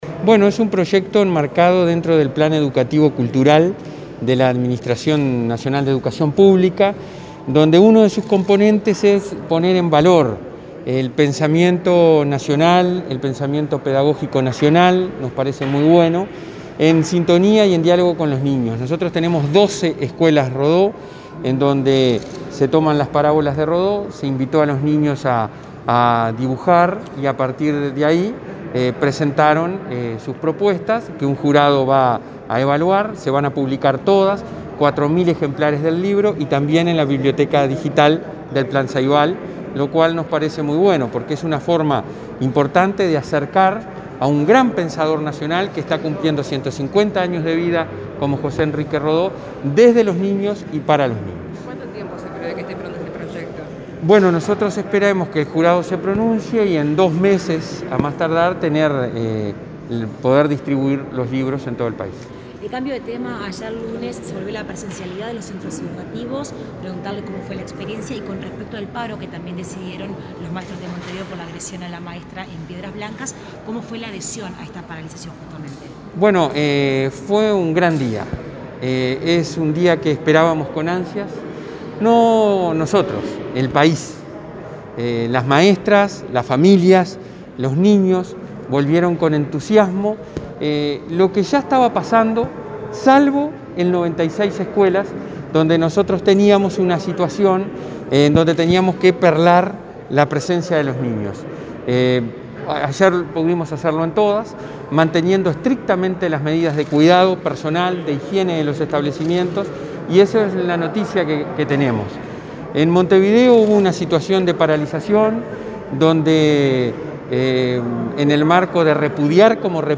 Declaraciones a la prensa del presidente del Consejo Directivo Central de la ANEP, Robert Silva
Declaraciones a la prensa del presidente del Consejo Directivo Central de la ANEP, Robert Silva 03/08/2021 Compartir Facebook X Copiar enlace WhatsApp LinkedIn Finalizada la presentación del concurso de cuentos Dibujando a Rodó, realizada este martes 3 en la sede de la ANEP, Silva brindó declaraciones a los medios de prensa.